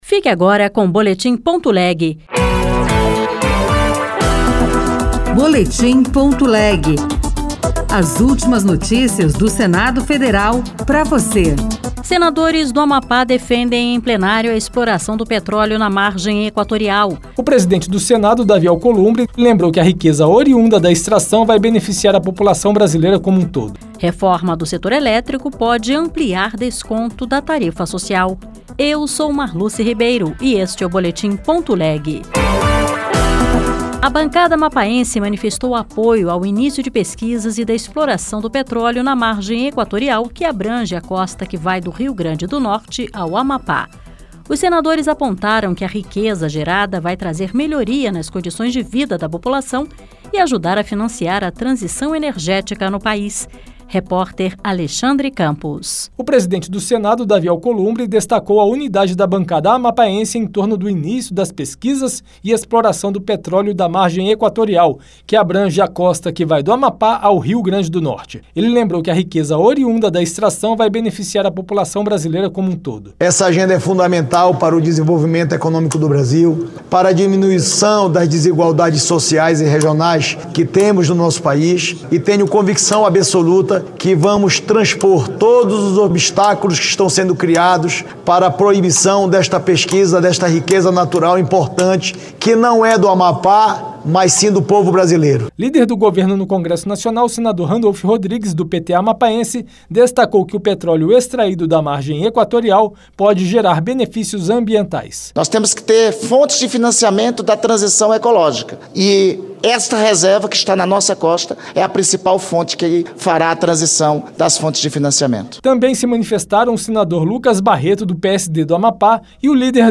Senadores do Amapá defendem em Plenário a exploração do petróleo na margem equatorial. Reforma do setor elétrico pode ampliar desconto da tarifa social.